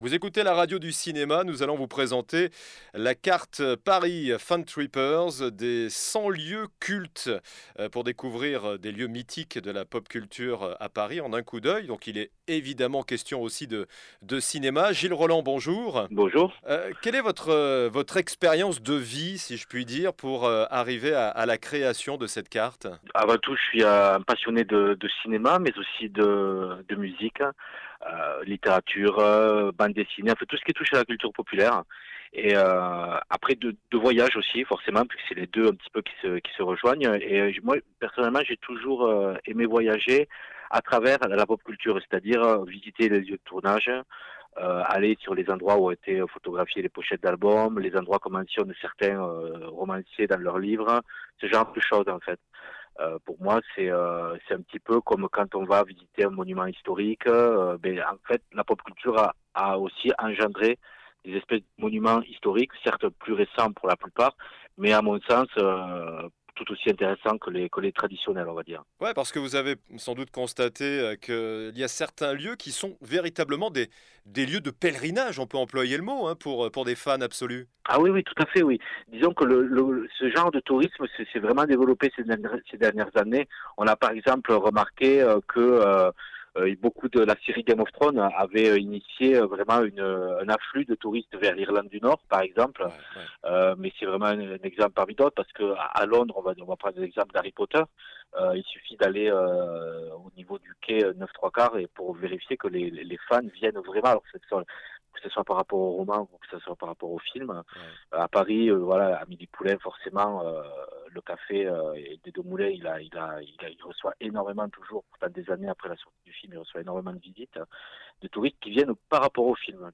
INTERVIEW: La carte Paris Fantrippers des 100 lieux cultes